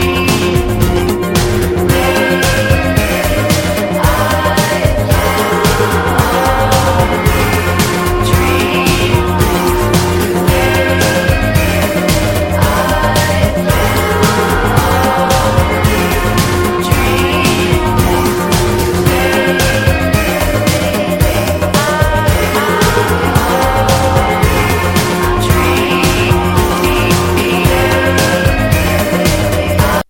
Electro / Krautrock / Alternative Synthpop Lp
Electro / Electronic Indie / Alternative Synthpop